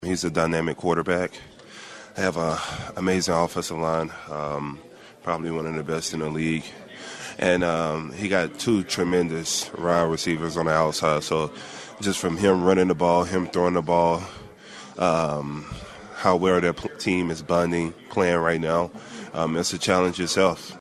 Chiefs defensive lineman Chris Jones says the Philadelphia offense and quarterback Jalen Hurts will provide a big Challenge.